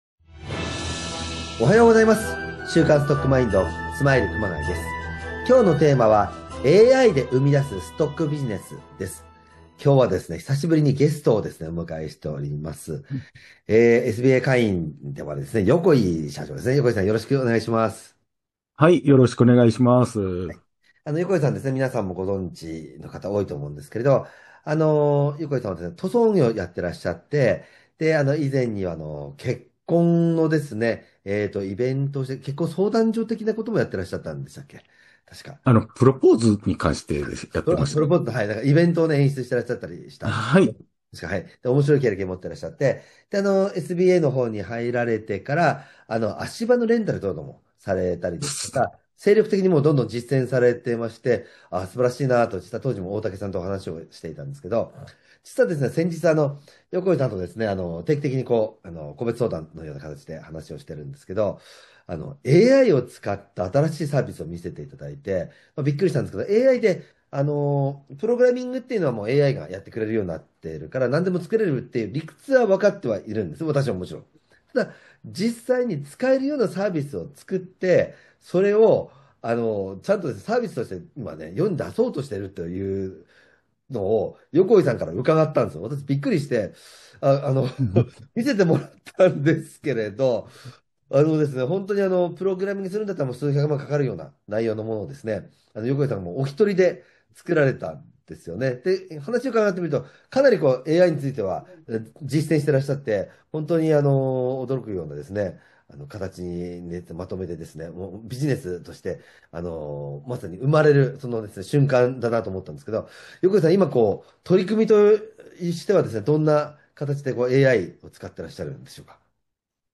本日のストックマインドは、久しぶりのゲスト回。